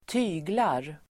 Uttal: [²t'y:glar]